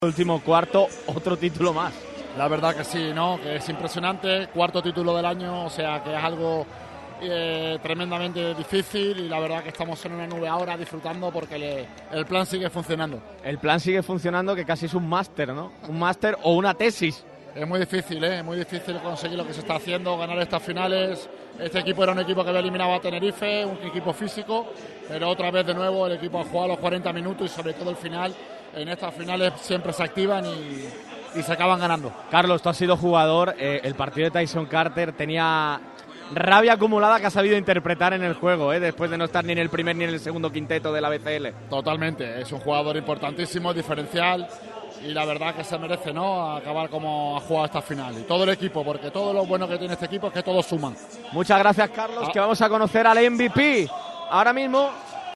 Las reacciones de los campeones de la BCL sobre el parqué del Sunel Arena.
CARLOS CABEZAS, EMBAJADOR DEL UNICAJA